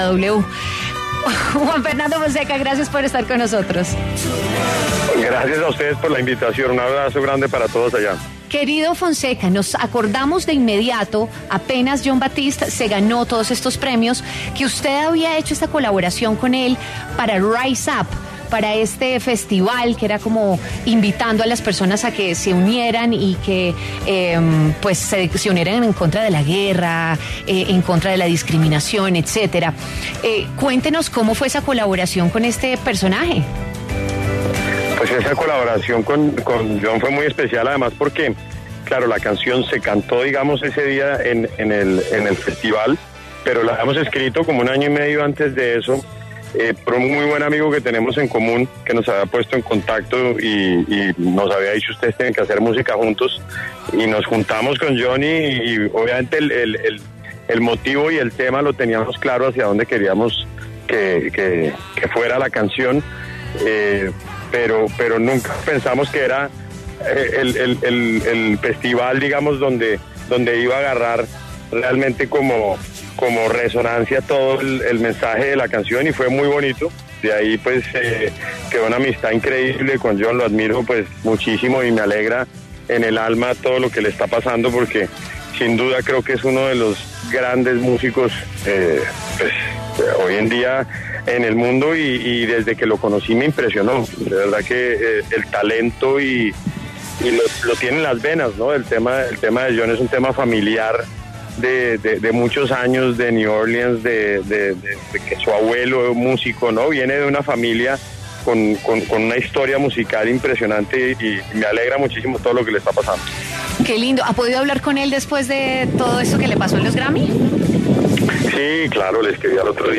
El cantante colombiano Fonseca habló sobre su próximo álbum en W Fin de Semana.